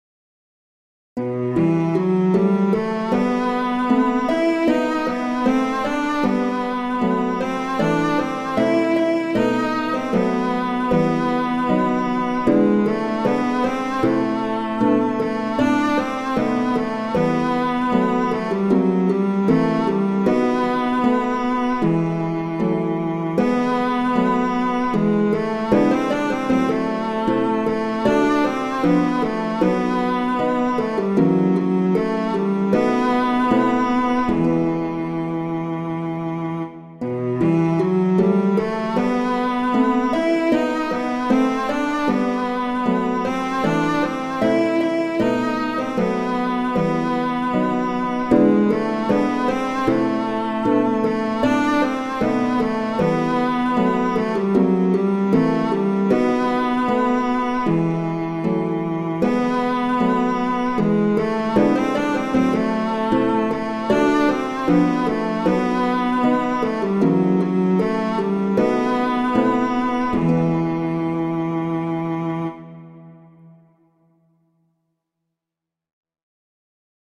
Instrumentation: cello & harp
arrangements for cello and harp